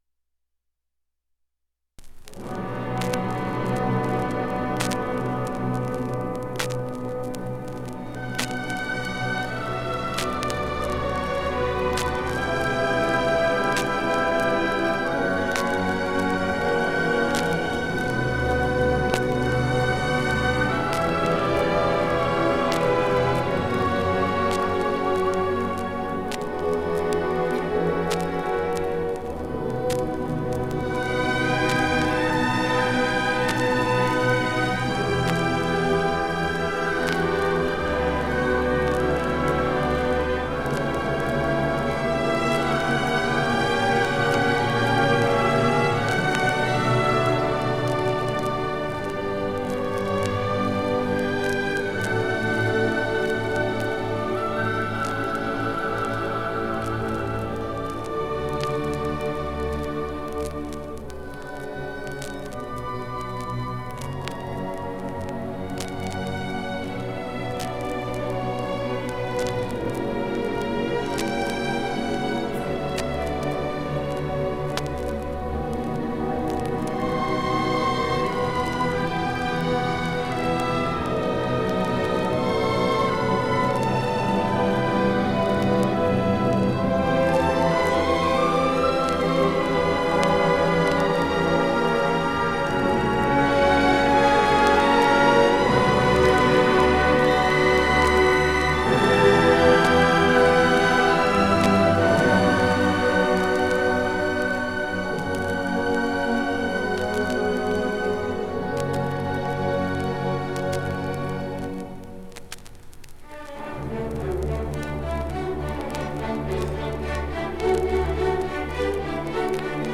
1975 Music in May orchestra and chorus performance recording · Digital Exhibits · heritage
5eb1172b46395e0c02f63c73cc4b55858b8eb1db.mp3 Title 1975 Music in May orchestra and chorus performance recording Description An audio recording of the 1975 Music in May orchestra and chorus performance at Pacific University. Music in May is an annual festival that has been held at Pacific University since 1948. It brings outstanding high school music students together on the university campus for several days of lessons and events, culminating in the final concert that this recording preserves.